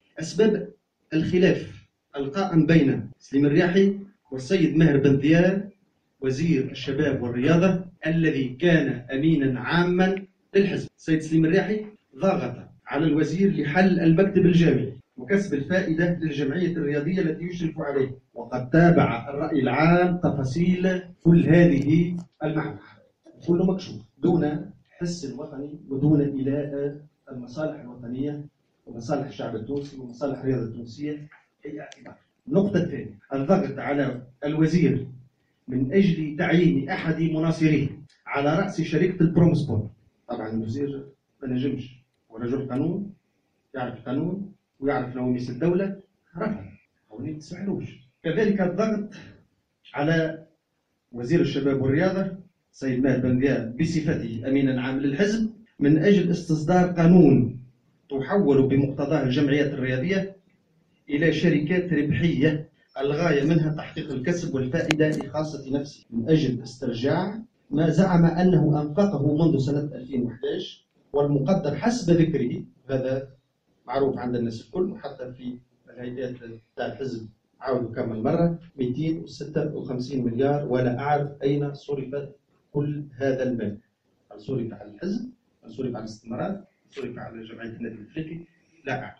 خلال ندوة صحفية عقدها اليوم الخميس 9 جوان 2016